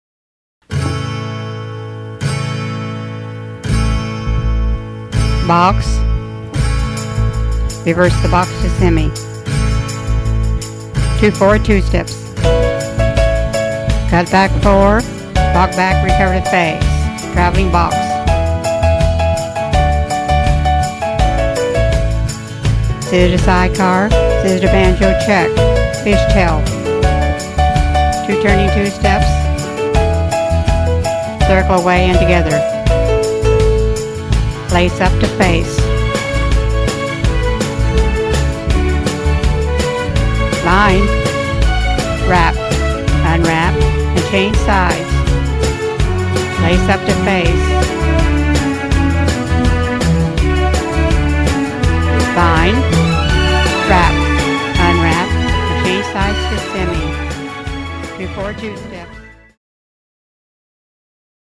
Two Step